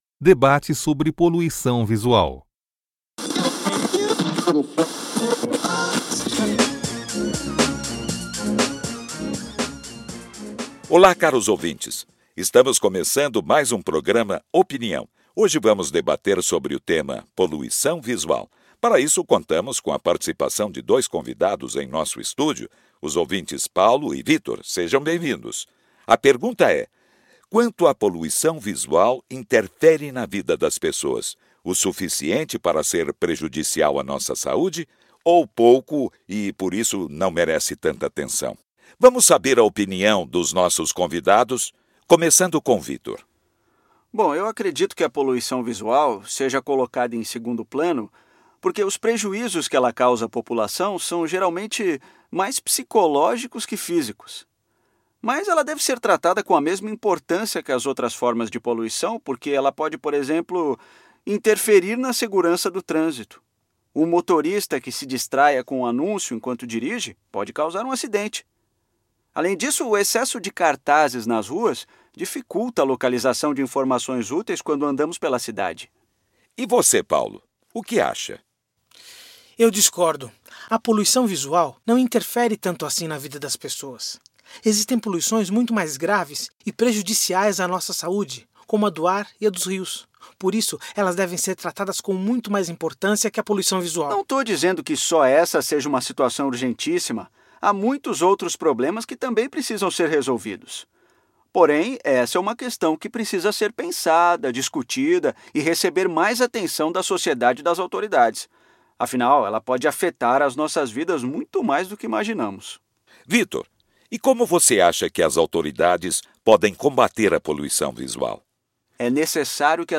Debate sobre poluição visual